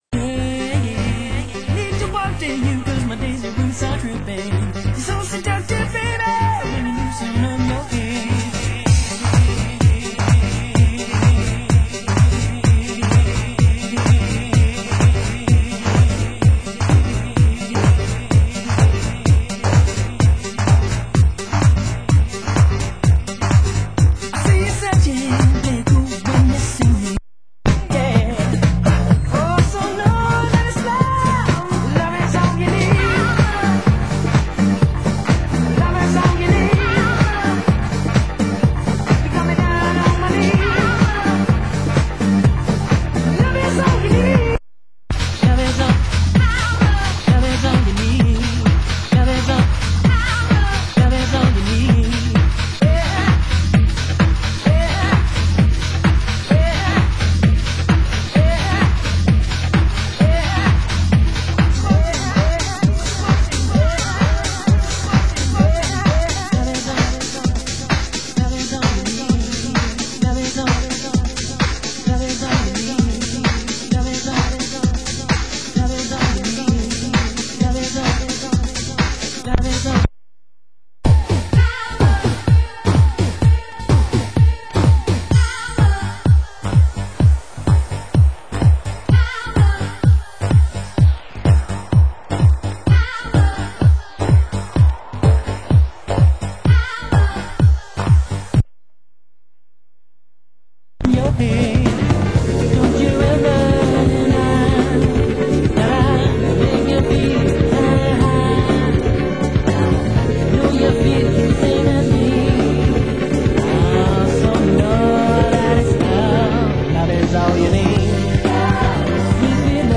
Format: Vinyl 12 Inch
Genre: Euro House